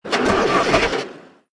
KART_Engine_start_0.ogg